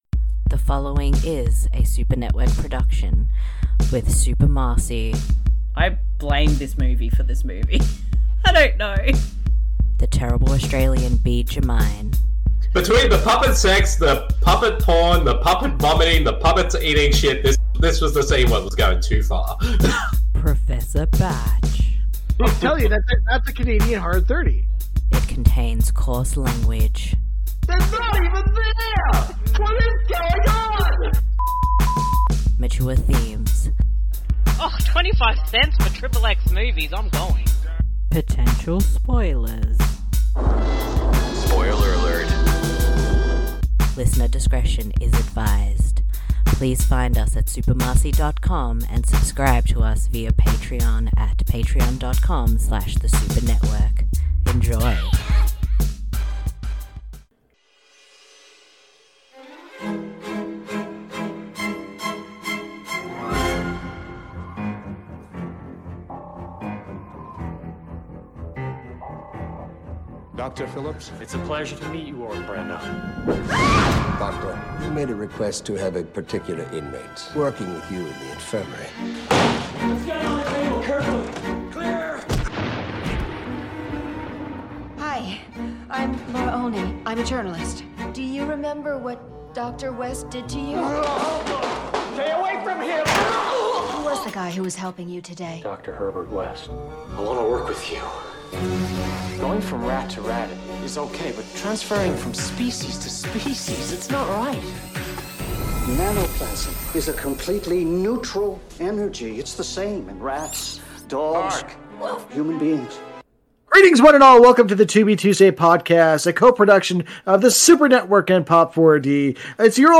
This podcast series is focused on discovering and doing commentaries for films found on the free streaming service Tubi, at TubiTV
You simply need to grab a copy of the film or load it up on Tubi (you may need alcohol), and sync up the podcast audio with the film.